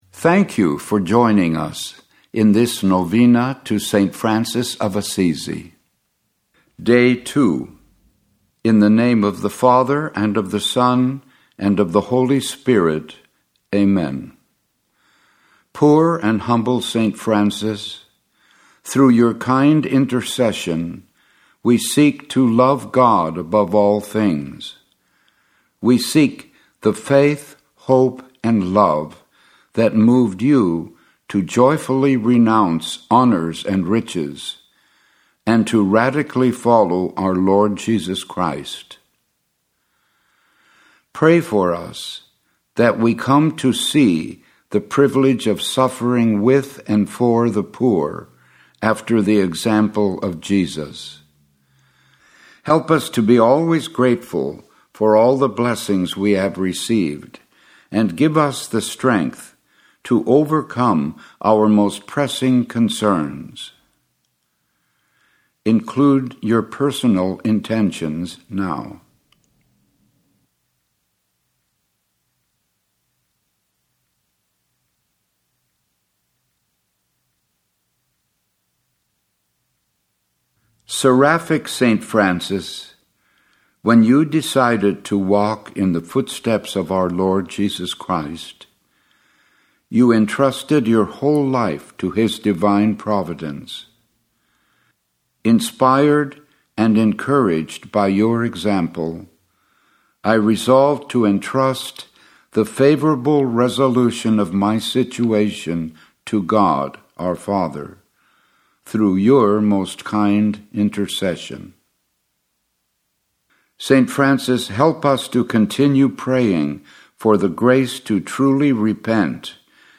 The Novena to St. Francis of Assisi